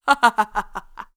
laugh1.wav